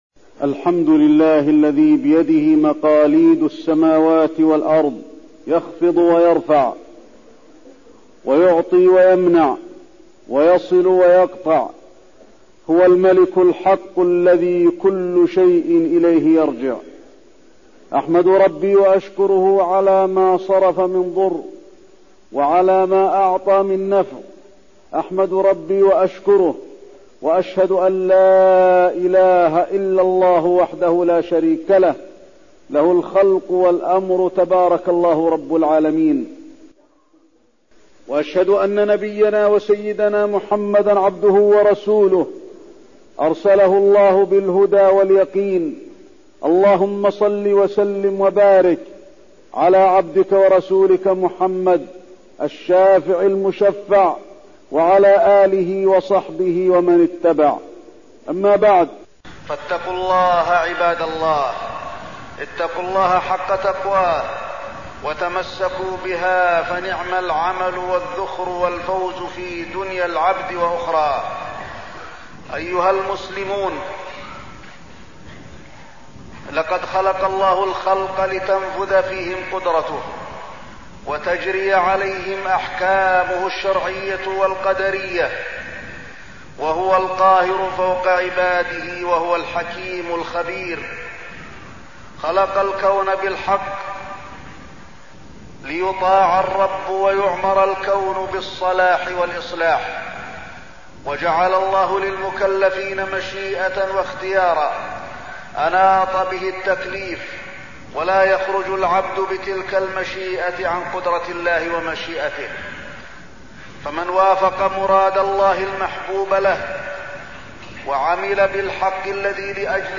تاريخ النشر ١٨ رجب ١٤١٧ هـ المكان: المسجد النبوي الشيخ: فضيلة الشيخ د. علي بن عبدالرحمن الحذيفي فضيلة الشيخ د. علي بن عبدالرحمن الحذيفي أحوال أهل الجنة والنار